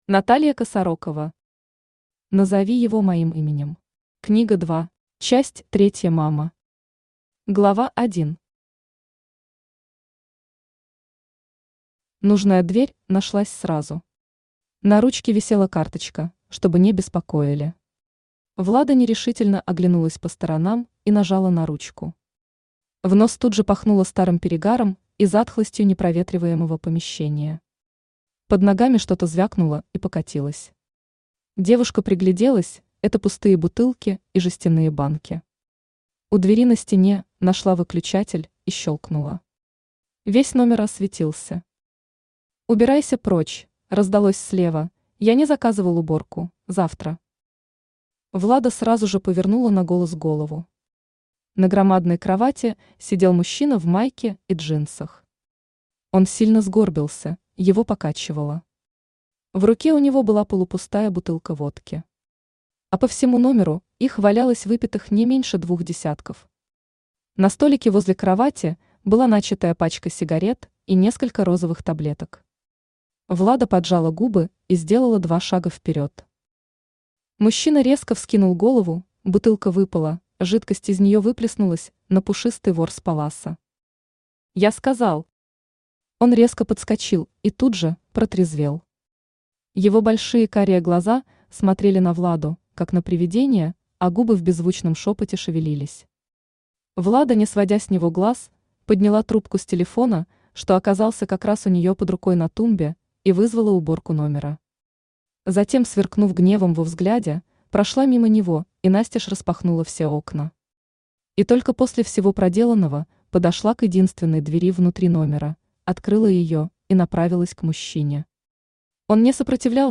Аудиокнига Назови его моим именем. Книга 2 | Библиотека аудиокниг
Книга 2 Автор Наталья Владимировна Косарокова Читает аудиокнигу Авточтец ЛитРес.